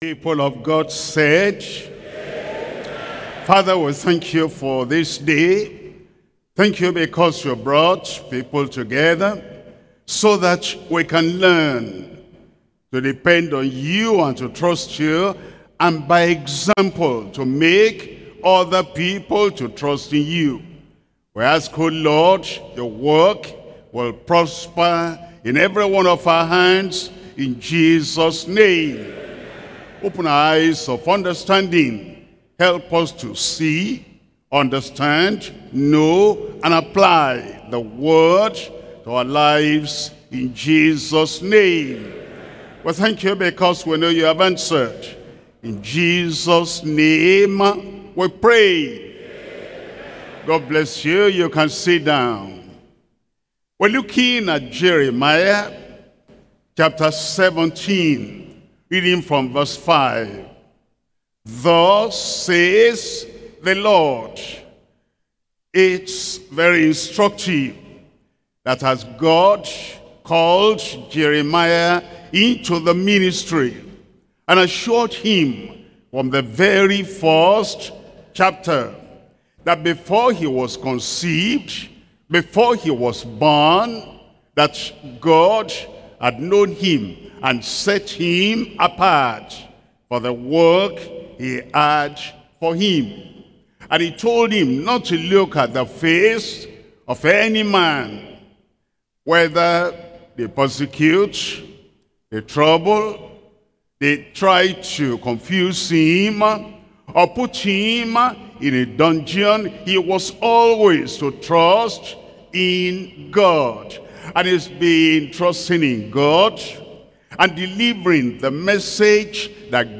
Sermons – Deeper Christian Life Ministry, Scotland Region, UK